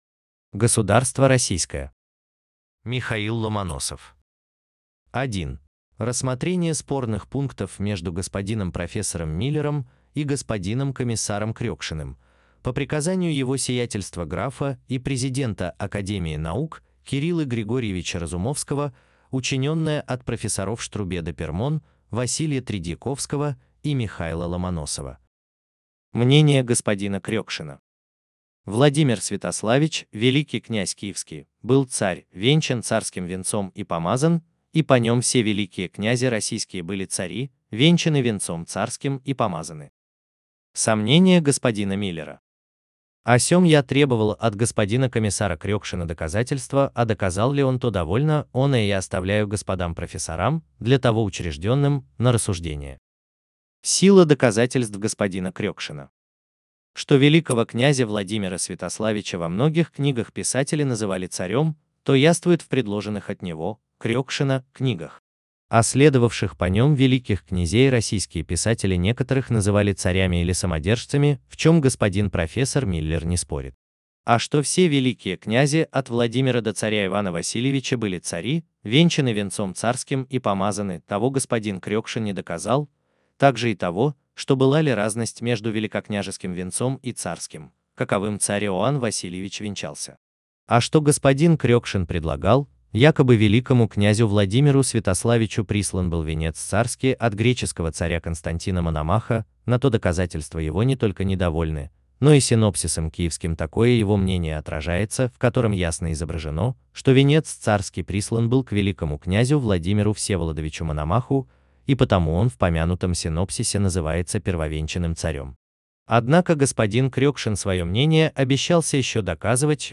Аудиокнига Древняя Российская история | Библиотека аудиокниг